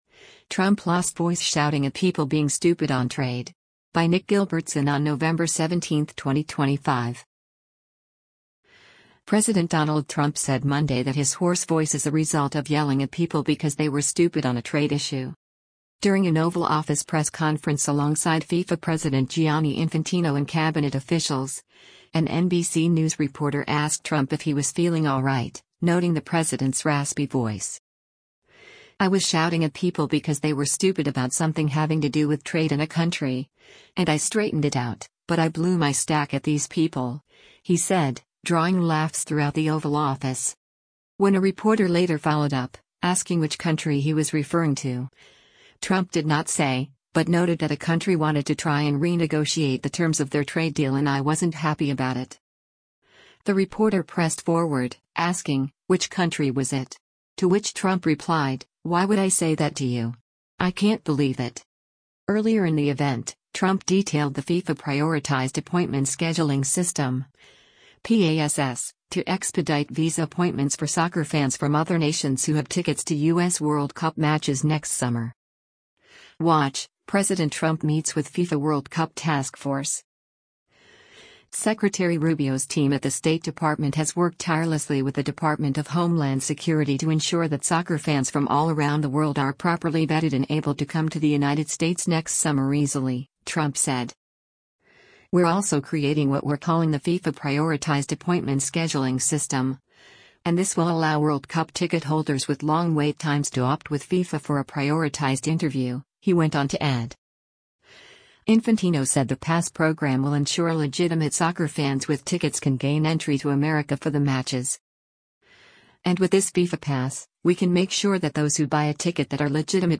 President Donald Trump said Monday that his hoarse voice is a result of yelling at people “because they were stupid” on a trade issue.
During an Oval Office press conference alongside FIFA President Gianni Infantino and cabinet officials, an NBC News reporter asked Trump if he was feeling alright, noting the president’s raspy voice.
“I was shouting at people because they were stupid about something having to do with trade and a country, and I straightened it out, but I blew my stack at these people,” he said, drawing laughs throughout the Oval Office.